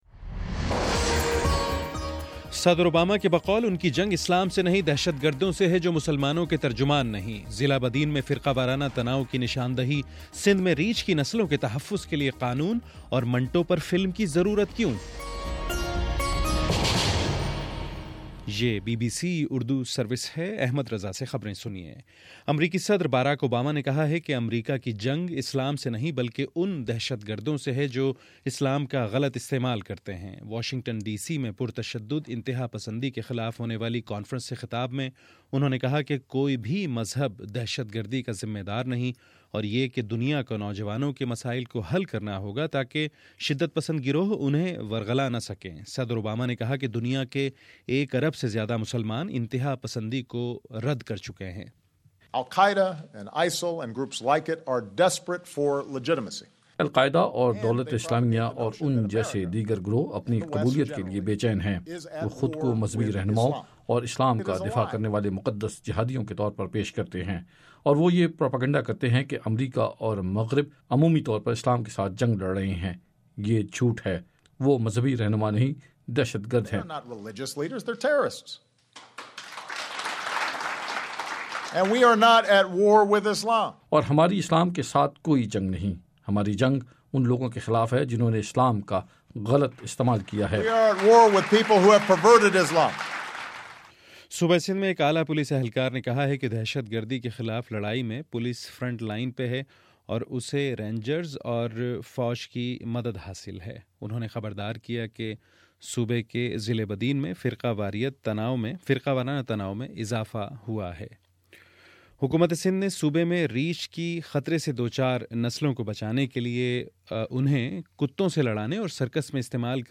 فروری 19 : صبح نو بجے کا نیوز بُلیٹن